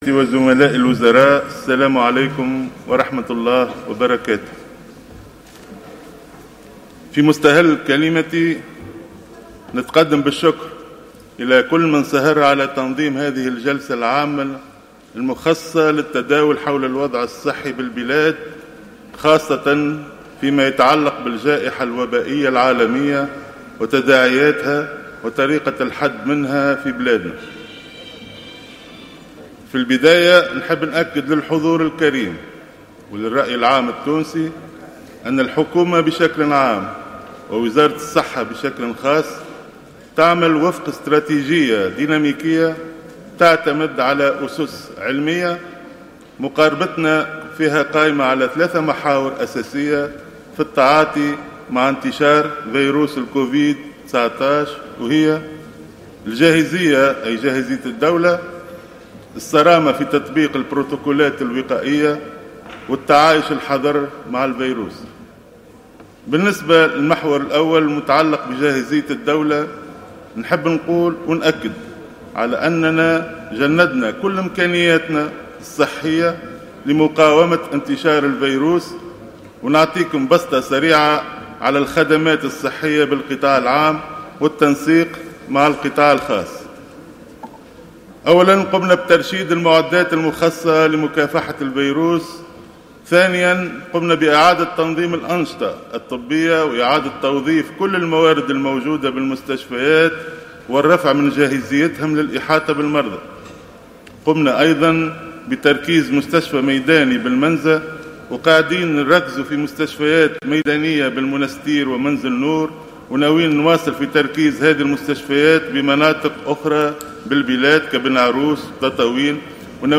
قال وزير الصحة فوزي المهدي خلال الجلسة العامة المخصصة اليوم للحوار مع عدد من أعضاء الحكومة بالبرلمان إن الحجر الصحي العام لم يعد ممكنا بعد اليوم.